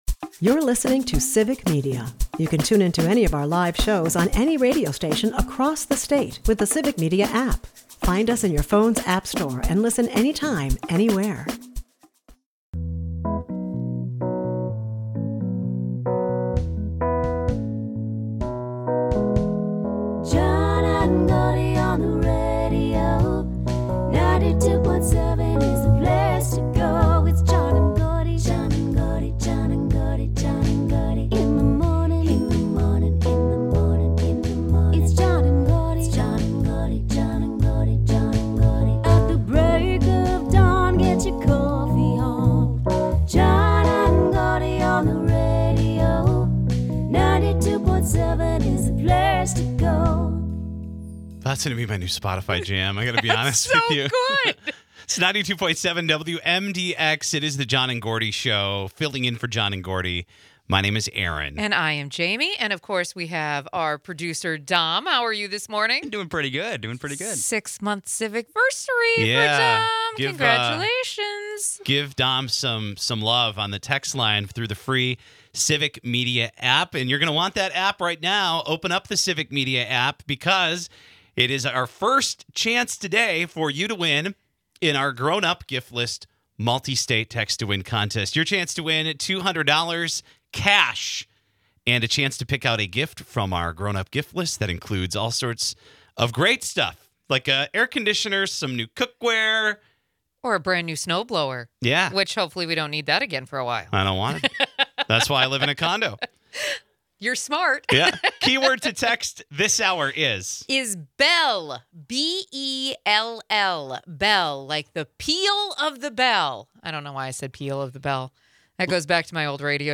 It's a lively mix of holiday spirit and humor.